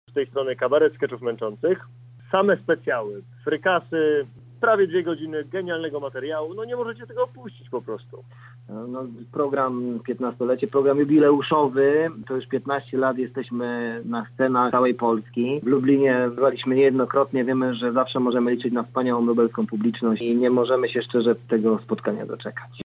zapraszają słuchaczy na wydarzenie: